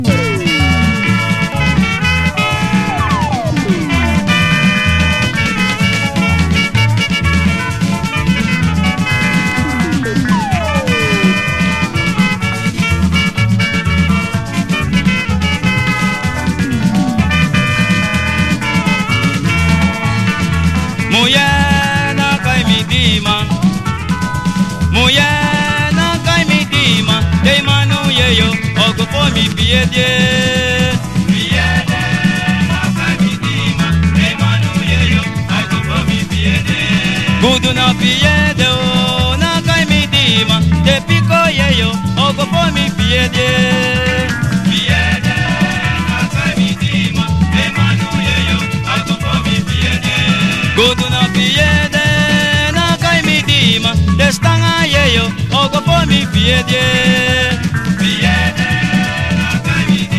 WORLD / OTHER / CHUTNEY / KASEKO / CARIBBEAN
突然変異的カリビアン・ファンク/インディアン・カリプソ7インチ！